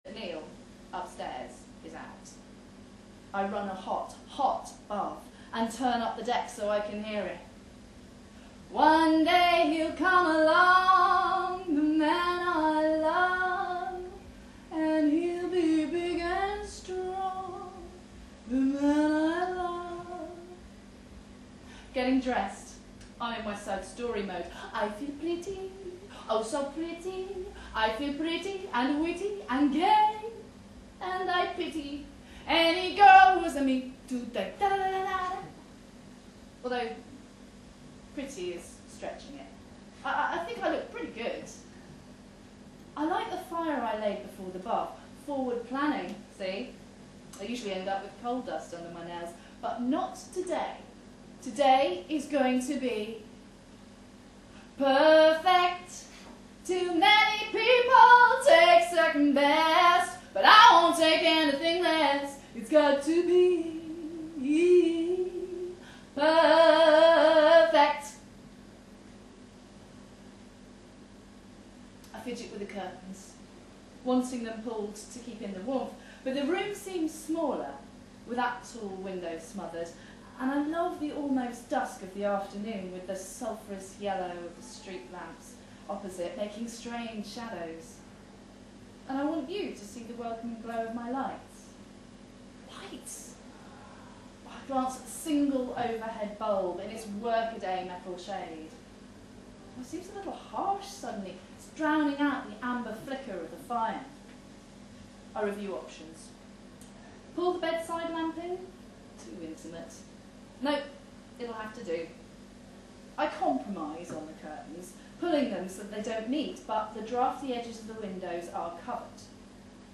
A collection of recordings of me (or actors) performing my stories live to audiences.